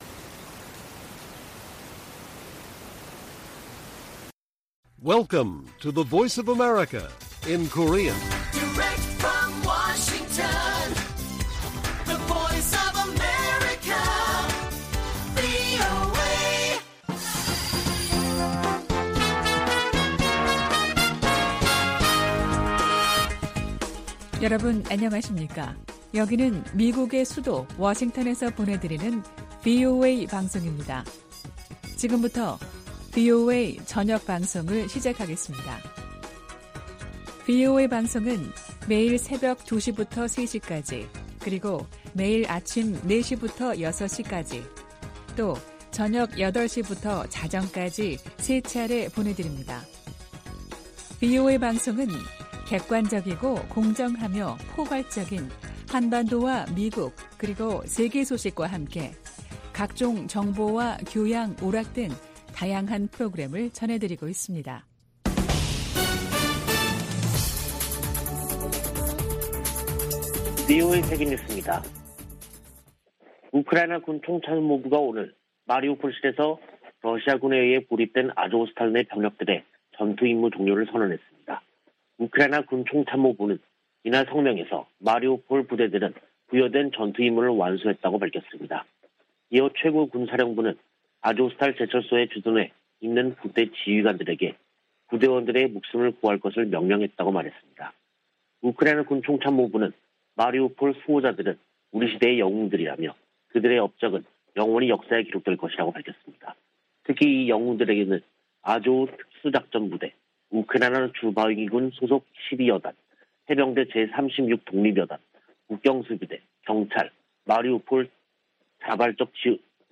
VOA 한국어 간판 뉴스 프로그램 '뉴스 투데이', 2022년 5월 17일 1부 방송입니다. 북한은 연일 신종 코로나바이러스 감염증 발열자가 폭증하는 가운데 한국 정부의 방역 지원 제안에 답하지 않고 있습니다. 세계보건기구가 북한 내 급속한 코로나 확산 위험을 경고했습니다. 북한의 IT 기술자들이 신분을 숨긴 채 활동하며 거액의 외화를 벌어들이고 있다고 미국 정부가 지적했습니다.